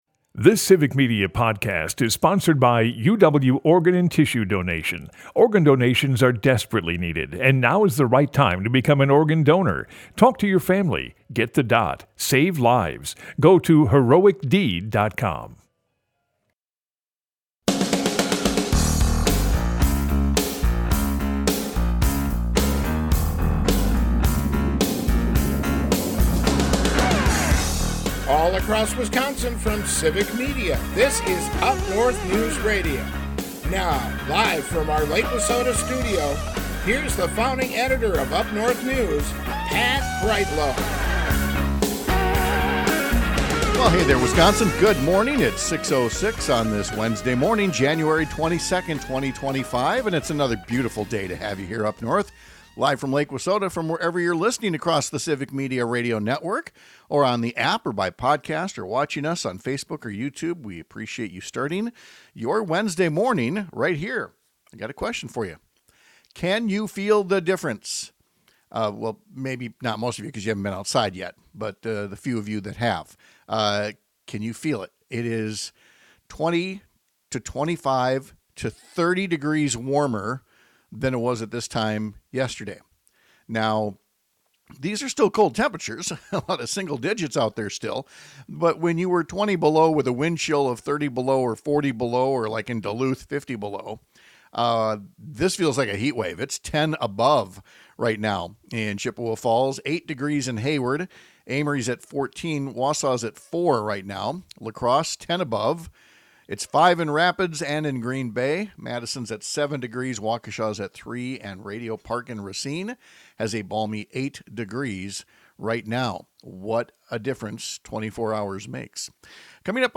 We’ll hear from folks who attended an event designed to lift the voices of people who face being attacked and marginalized over the next four years.